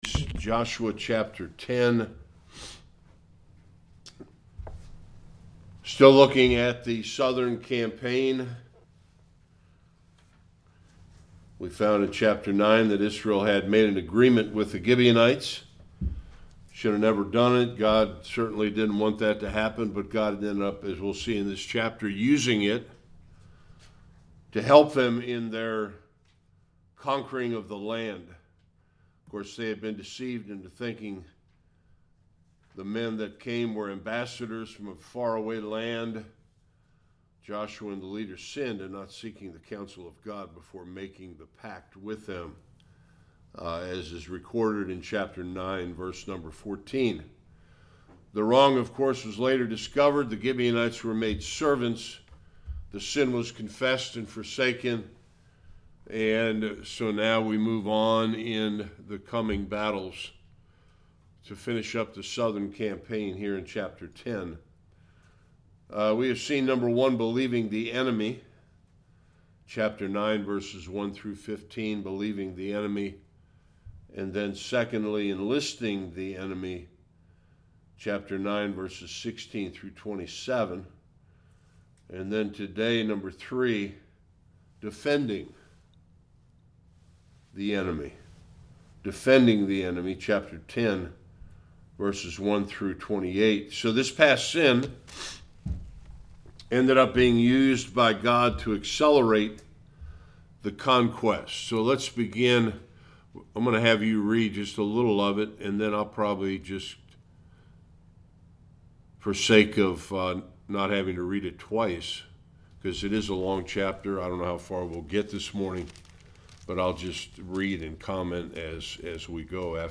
1-43 Service Type: Sunday School The conclusion of the Southern campaign.